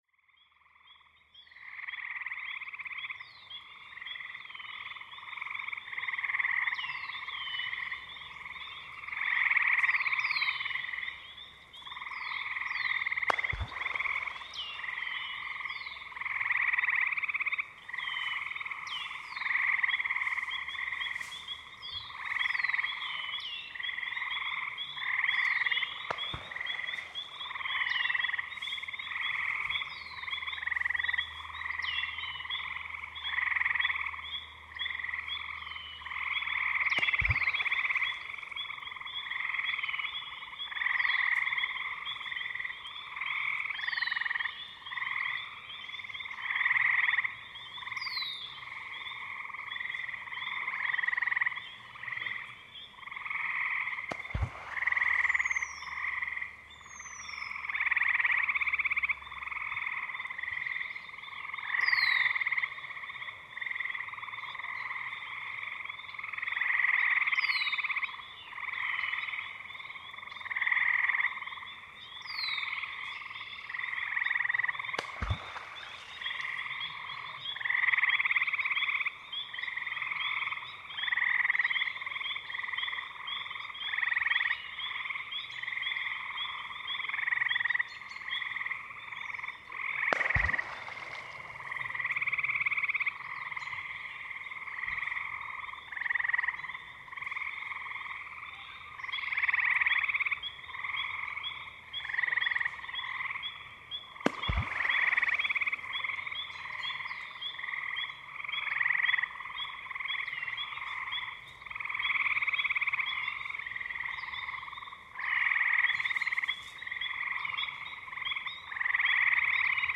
Beaver splash and the crater lake band
In the summer of 2019, while sitting beside a small unnamed lake/pond, which friends and I called Crater Lake (because it looked like a crater), I was attempting to record loons in the late afternoon/early evening. Instead, a beaver swam around circling and splashing about near the nest of a pair of loons and their loonlets, messing with them far as I could tell. Thus, we get no loon sounds but some reverberating beaver tail whips.
The lake itself was situated where I was sat several meters from the shore nestled in an almost amphitheater type setting, and the recording reflects as much (especially when listening with headphones or a good pair of speakers). The frogs, songbirds set the stage for a sound rich environment, and the beaver tail led the way, so to speak, or at the very least, punctuated the recording with deep bass resonance.
I find the tail whip sounds especially unique and felt serendipitous I was able to capture it - sounds of the northwoods at its finest.